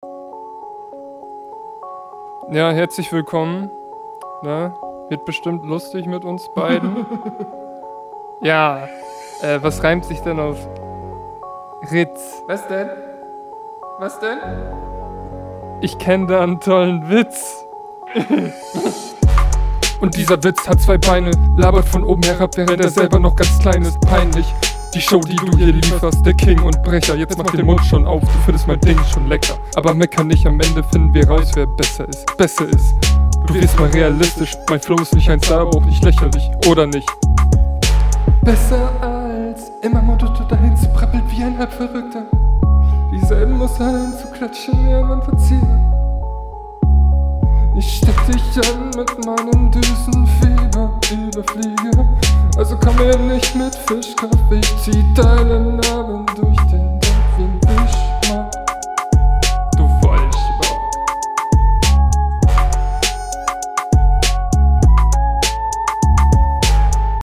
Alles noch sehr stockend, deine …
Mische: Stimme viel zu laut, Doubles Asynchron aber nicht so sehr wie die HR bzw …